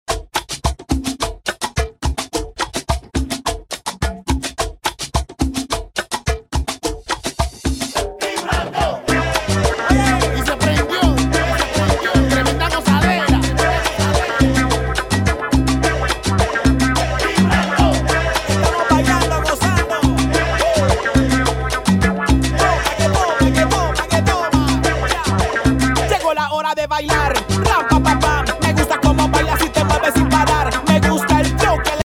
His style is unique and electrifying.